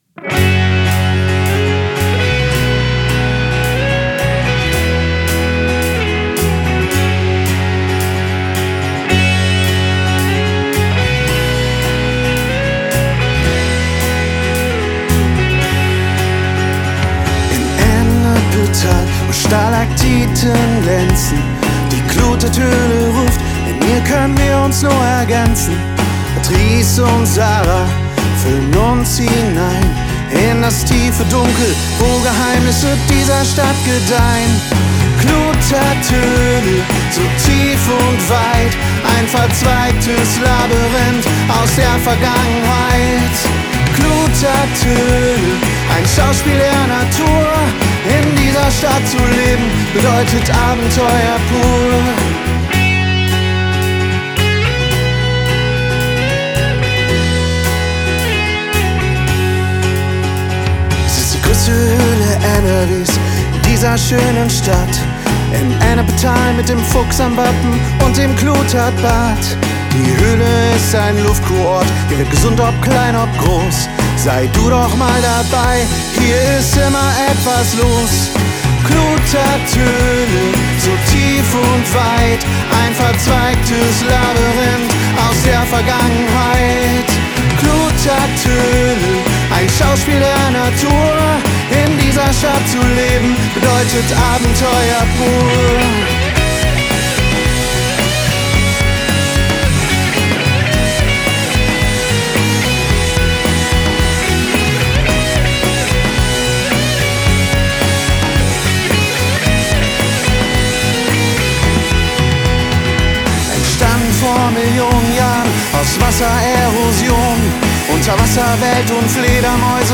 Coverrock